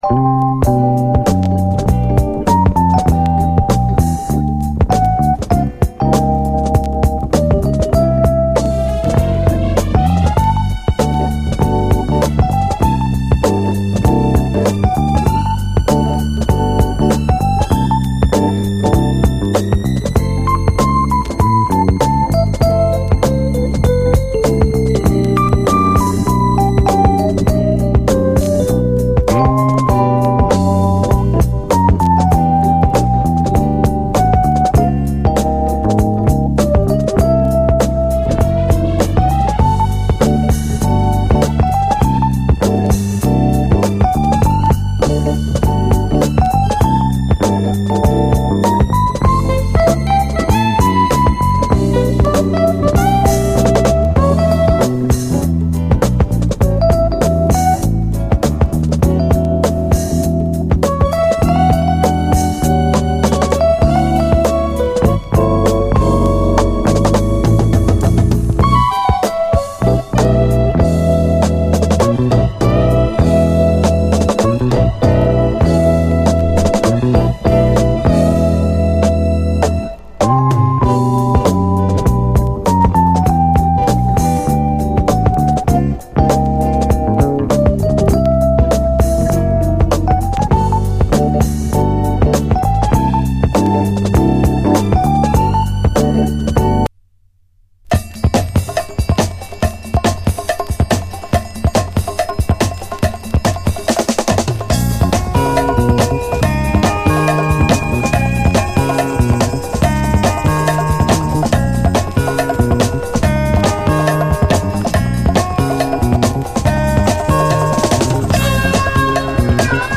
♪オオオオー・コーラスと王道シカゴ・ソウルのゴージャス・アレンジでフロアをシェイクする、キラー・ドリーミー・ソウル！
巧みなドリーミー・コーラスが舞うグレイト・スウィング！